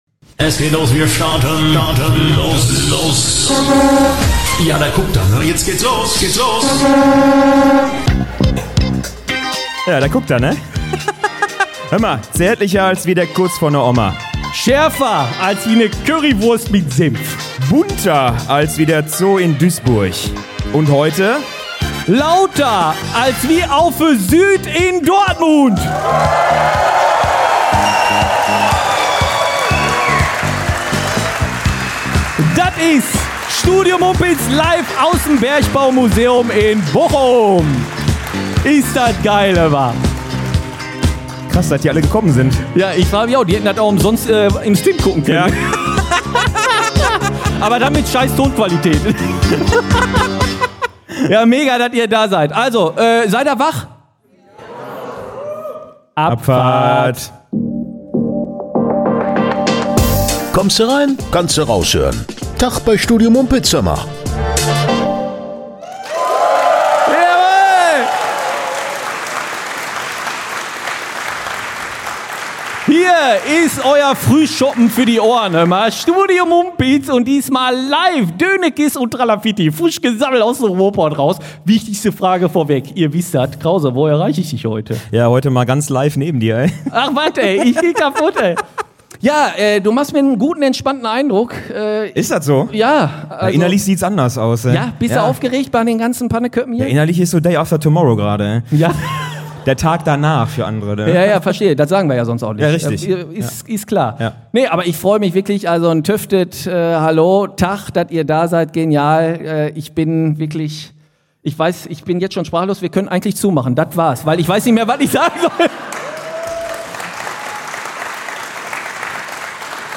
Live vor 200 Panneköppen im Bergbaumuseum Bochum. Von echte Liebeserklärungen, übba Zuhörers, die kurzfristich aufn Schacht mussten, bis hin zu Gurken, die wa uns in unsere Hackfressen geschmiert hamm, war allet dabei!